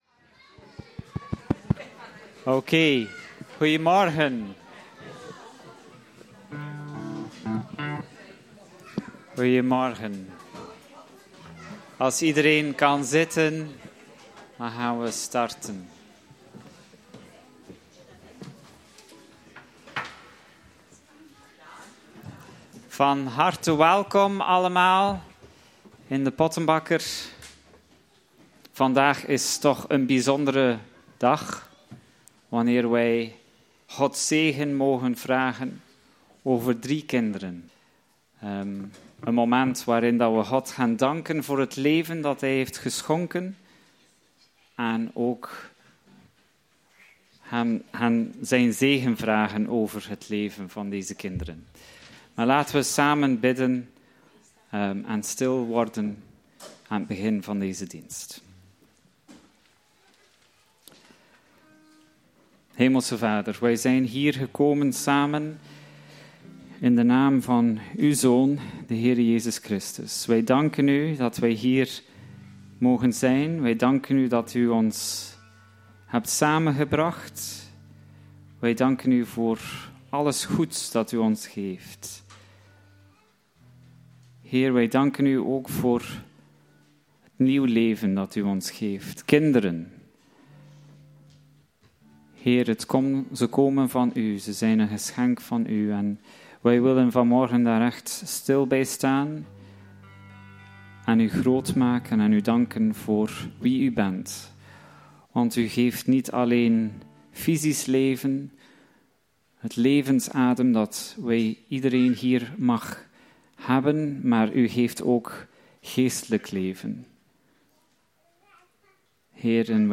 Filippenzen 1:1-8 en 2:12-18 Dienstsoort: Opdragingsdienst hoe omgaan met onze hopen en dromen voor onze kinderen?